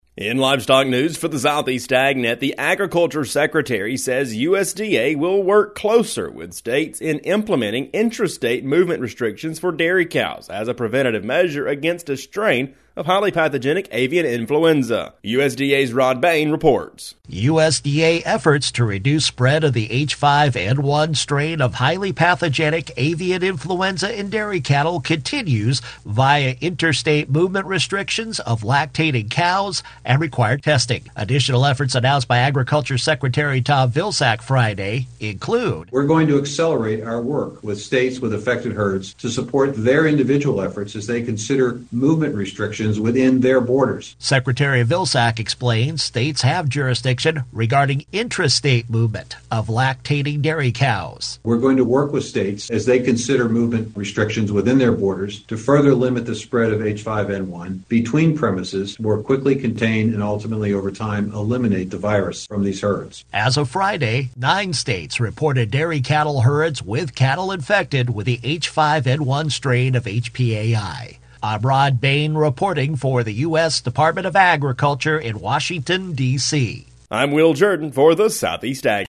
Reports.